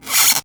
Index of /90_sSampleCDs/E-MU Producer Series Vol. 4 – Denny Jaeger Private/Effects/Scratch